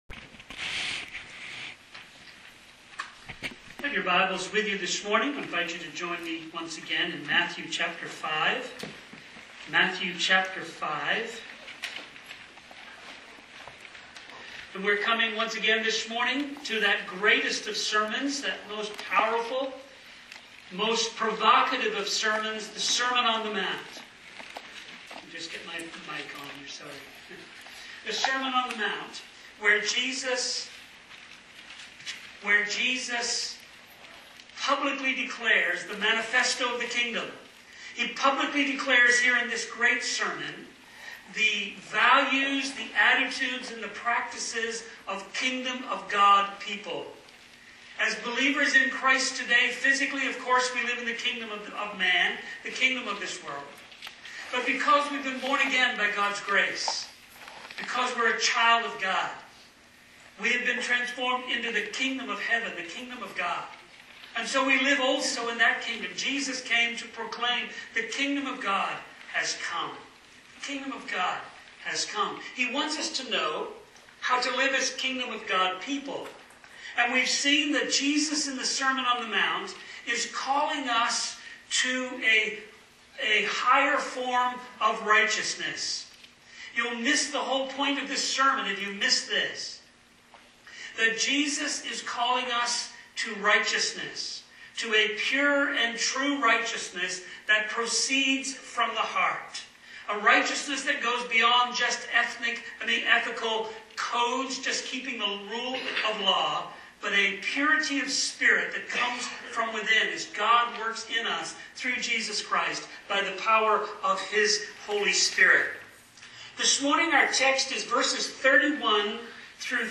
People-of-Integrity-Sermon-on-the-Mount-Series.mp3